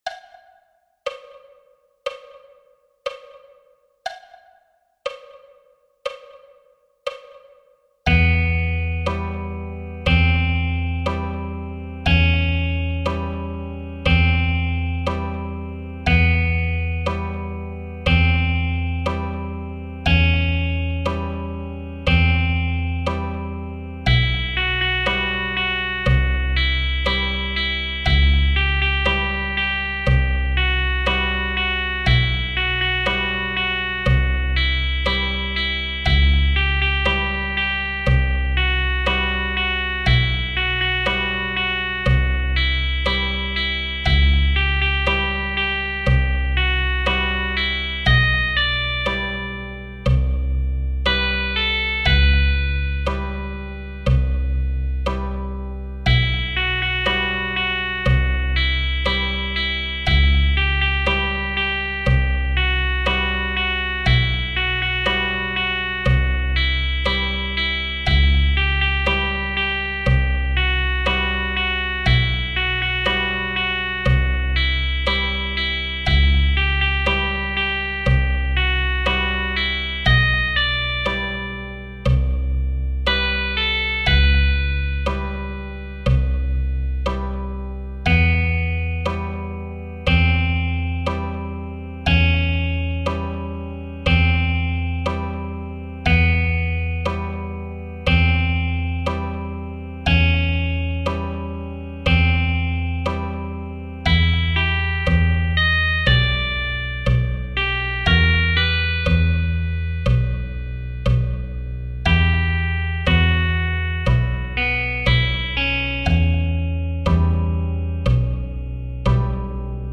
.mp3 File (slow version)